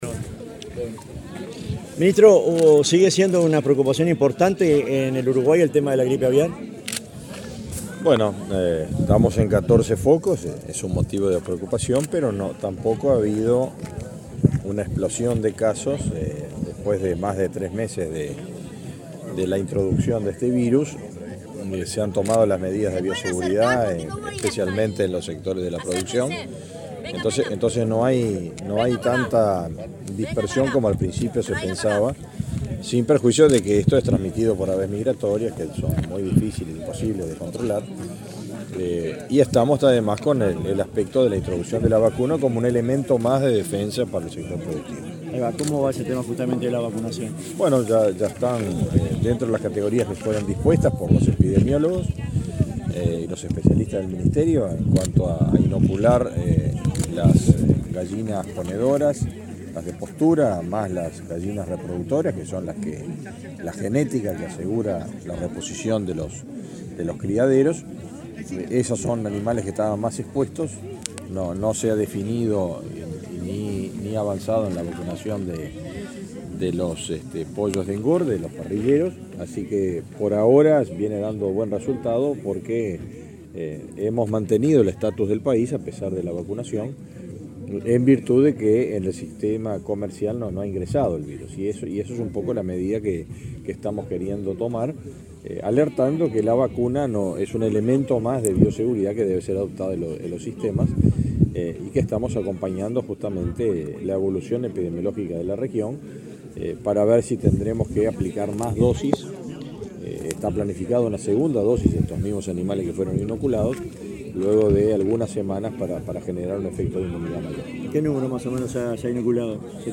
Declaraciones del ministro de Ganadería, Fernando Mattos
Luego dialogó con la prensa.